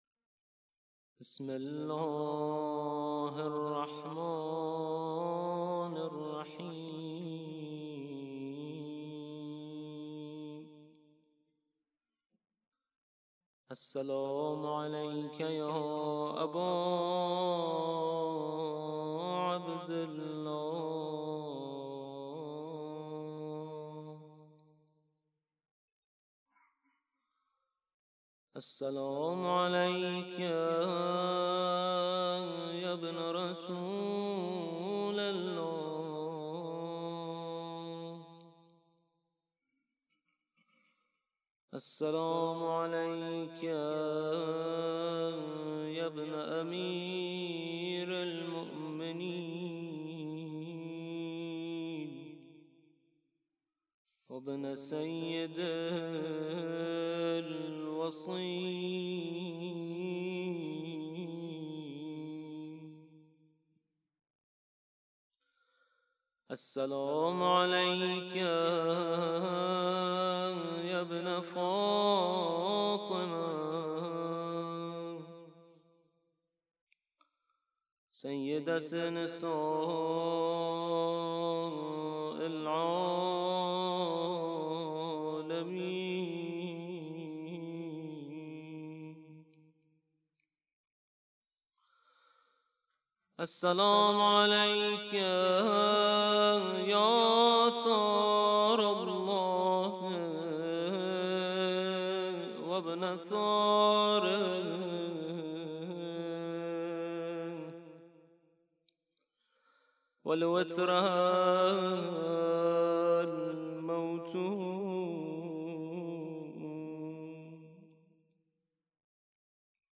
Ziarate_Ashura.mp3